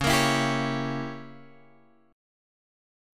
C# Diminished 7th